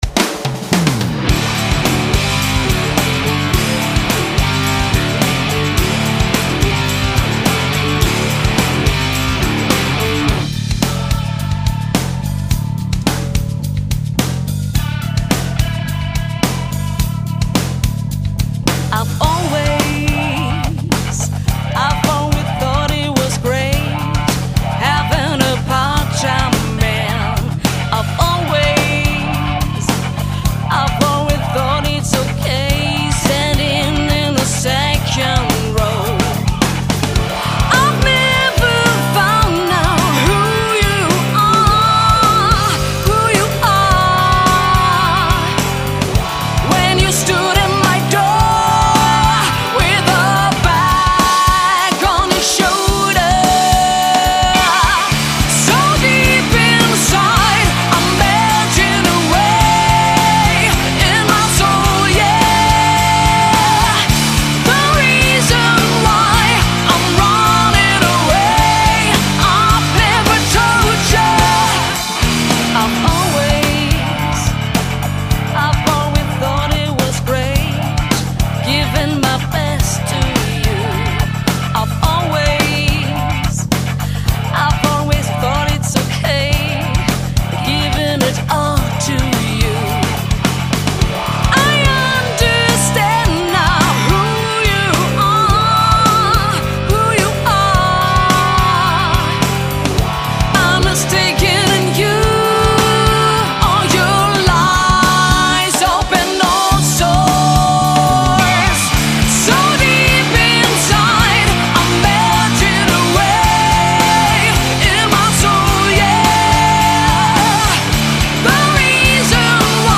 Rockband
Vocals
Guitar/bac.voc.
Drum